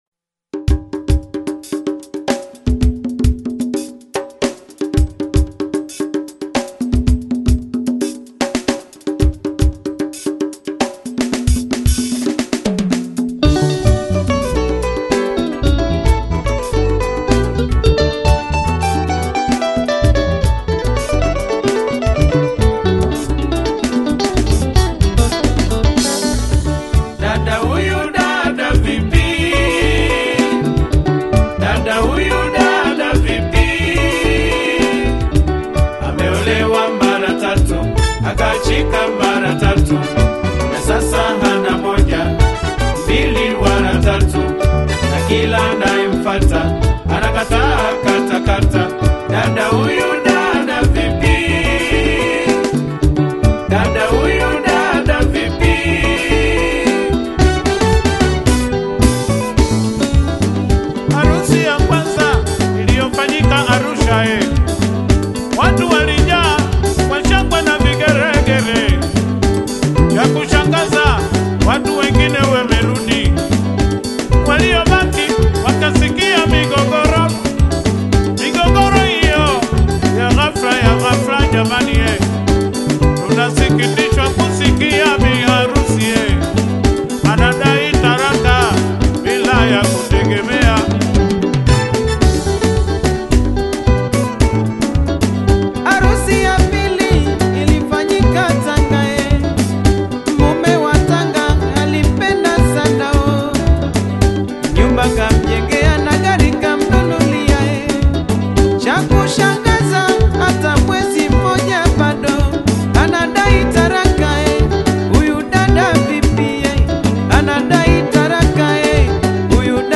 AudioTanzaniaZilipendwa